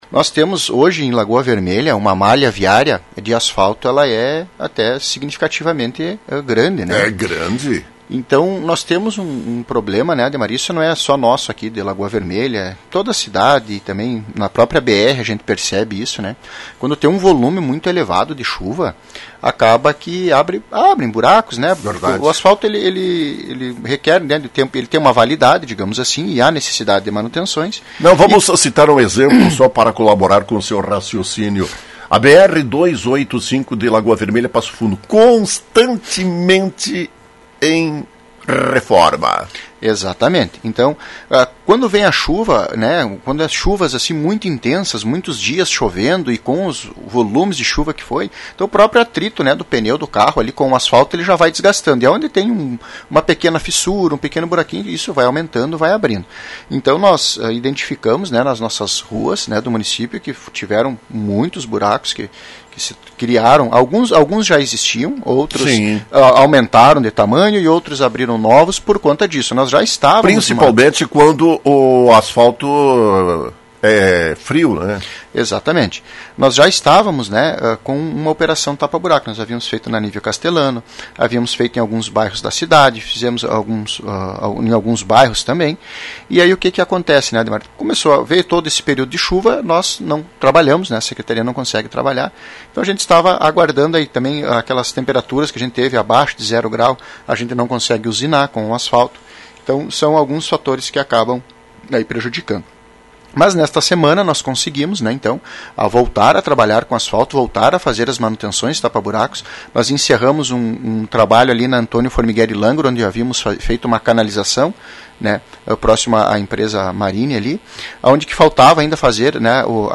As recentes chuvas provocaram muitos danos nas ruas de nossa cidade, principalmente nas ruas asfaltadas. Em razão disso, secretaria municipal de Obras e Viação está desenvolvendo uma operação tapa-buraco. Secretário Admilson Ferreira da Silva dá mais detalhes.